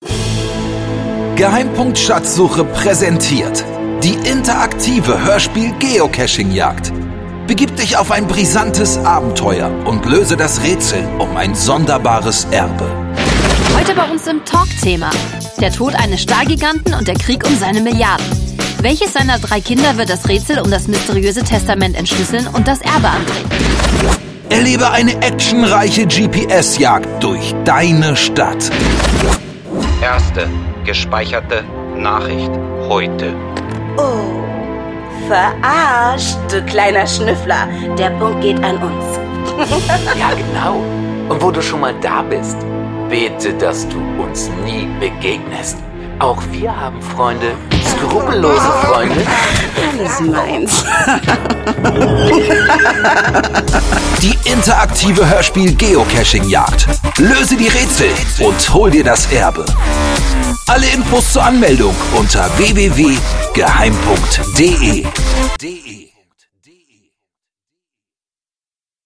das interaktive Hörbuch